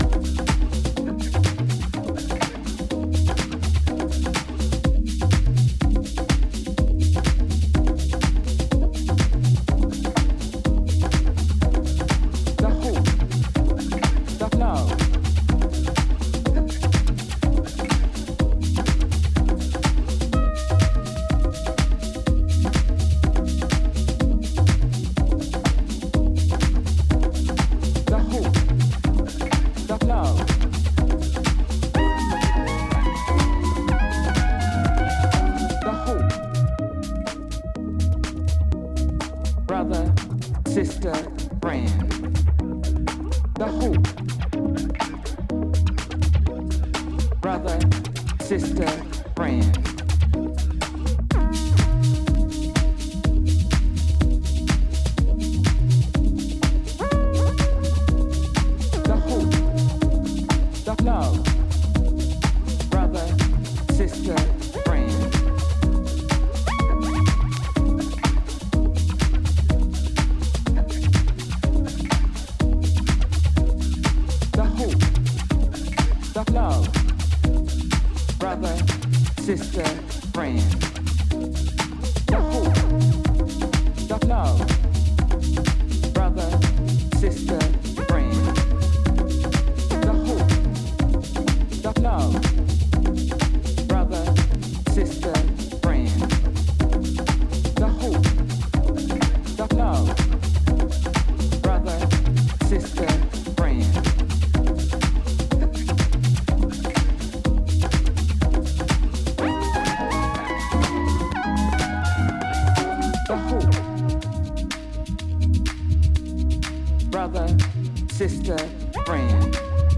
ボンゴを添えたオーガニックなグルーヴにスペーシー・シンセを添えた
いずれもジャジーでオーセンティックな魅力を秘めた